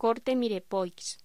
Locución: Corte mirepoix
voz